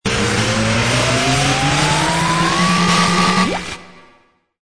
That last sound file sounds a lot like the end of a song I recorded in 2000.